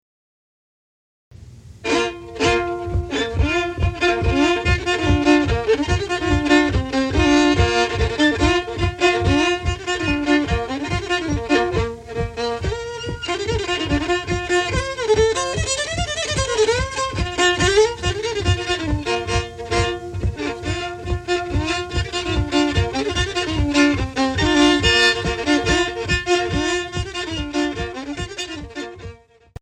Actually, it is a variant of the Circle Two-Step.  Forming a large ring, as many as twelve couples may participate in this dance, and the fiddlers adjust their violins to a special tuning that is not used with any of the other dances.